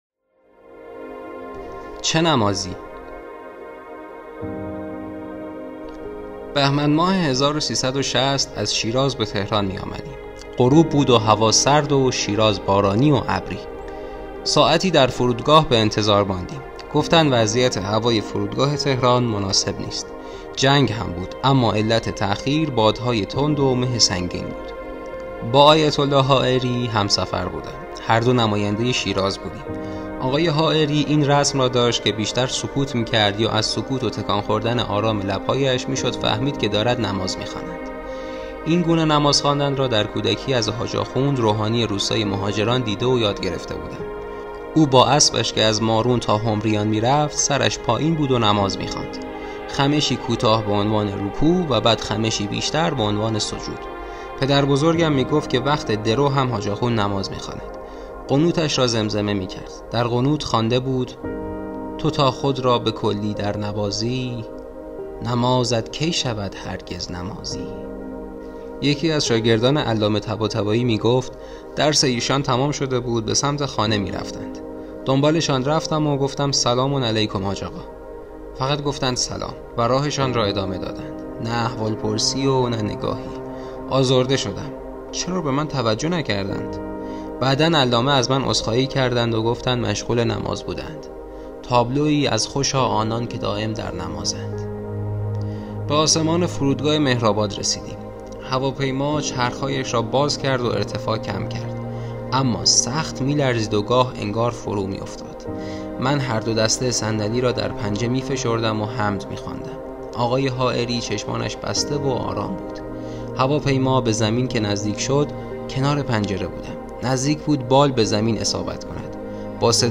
روایتی از کتاب «حاج آخوند» / ۱۰